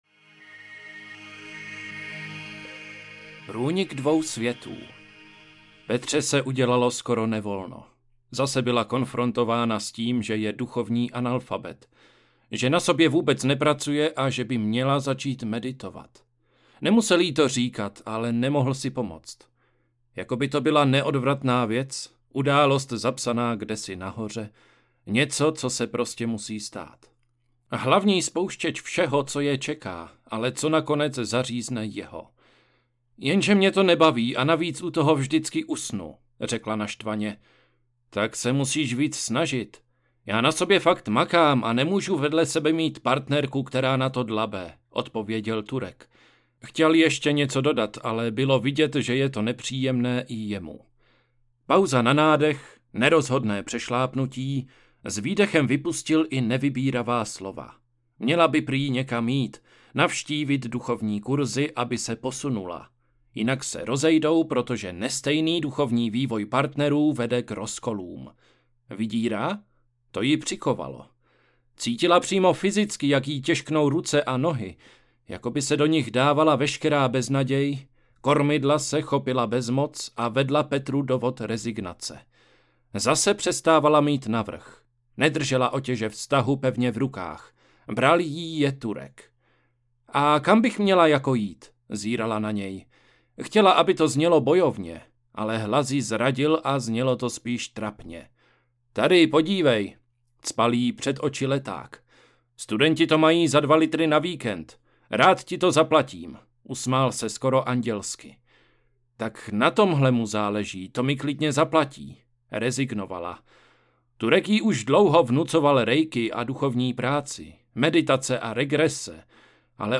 Lovci sexu, sběrači lásek audiokniha
Ukázka z knihy